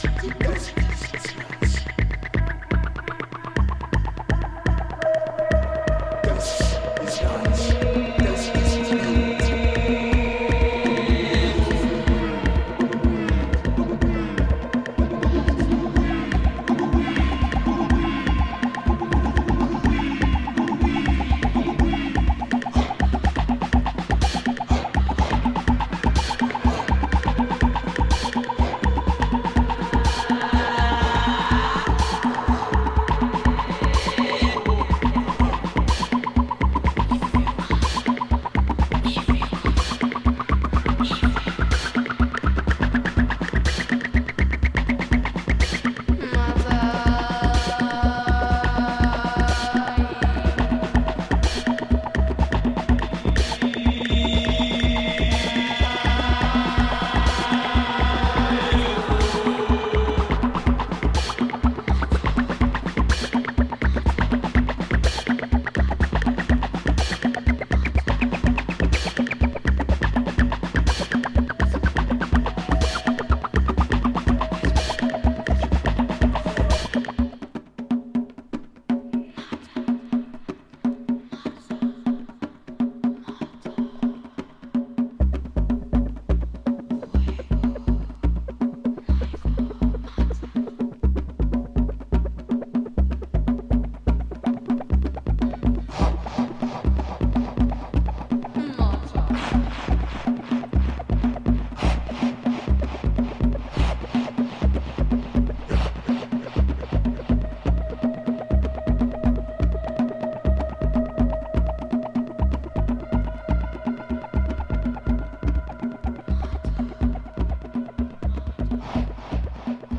Hiplife and Borborbor music
traditional Garifuna music